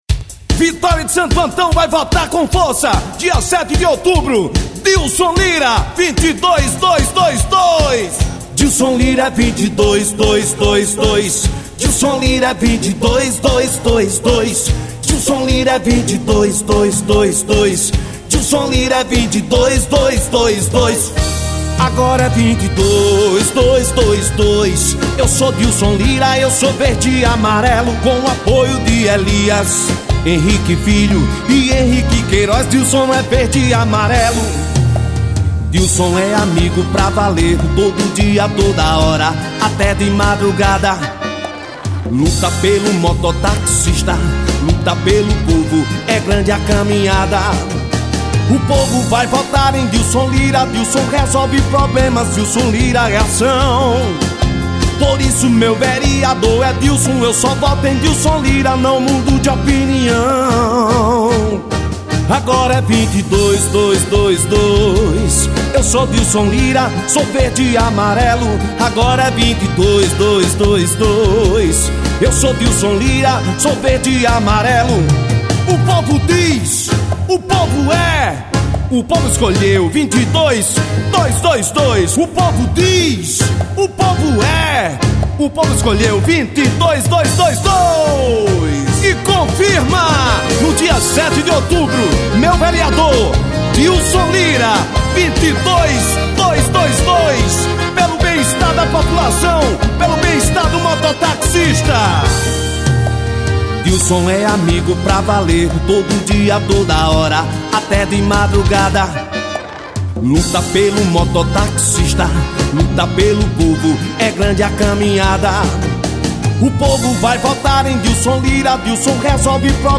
JINGLE POLÍTICOS.